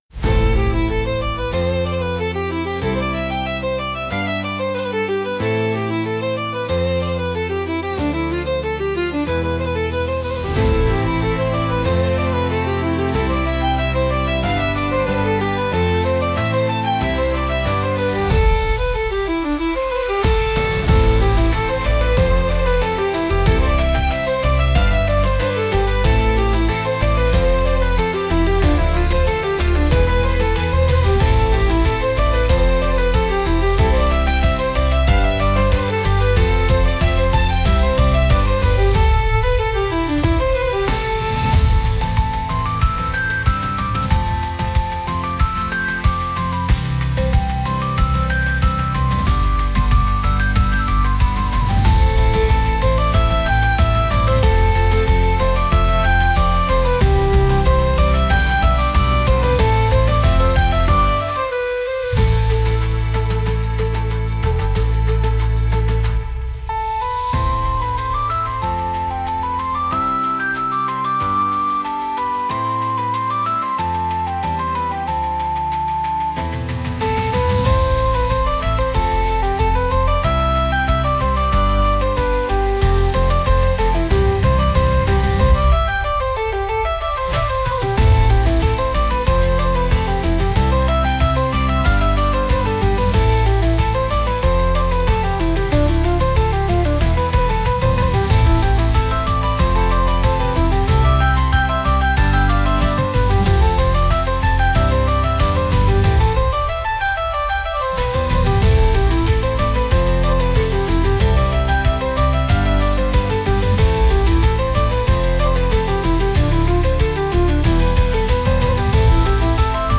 険しさの中に新たな地を見つける高揚感を感じるようなケルト音楽です。